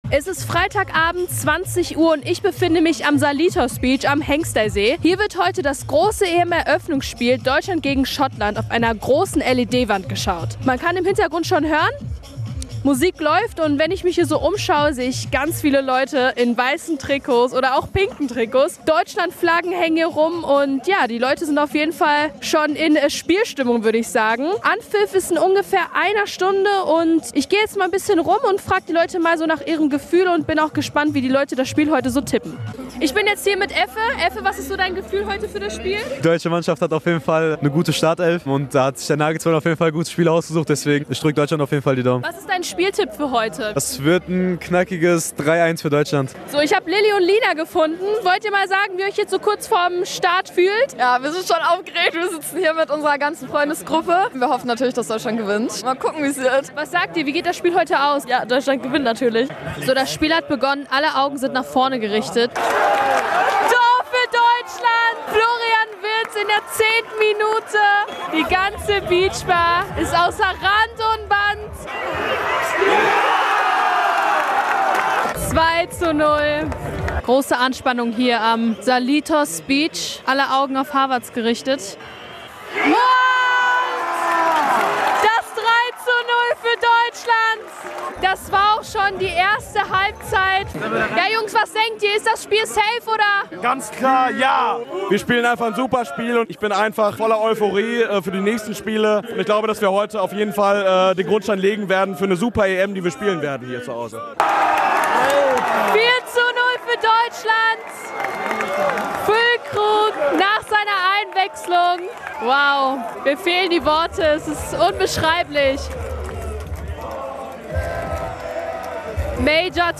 Hier gibt es die Reportage zum Spiel an der Salitos Beach